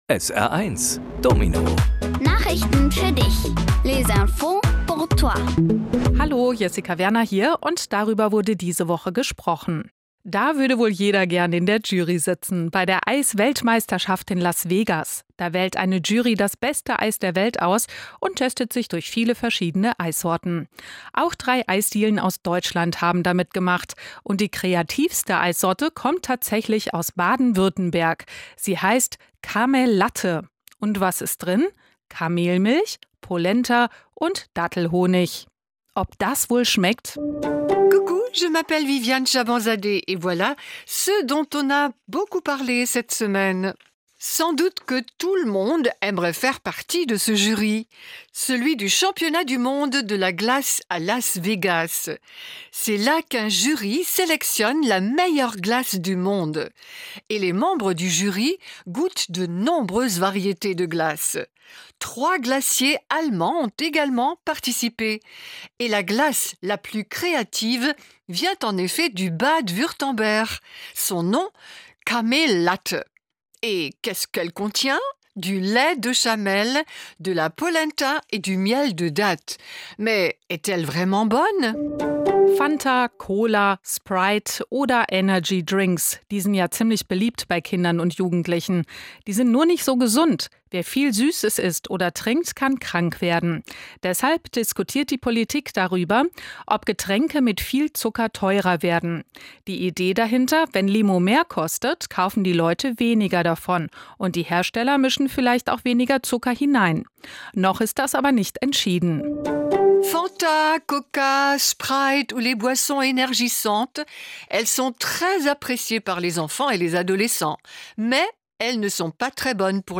Die wichtigsten Nachrichten der Woche kindgerecht aufbereitet auf Deutsch und Französisch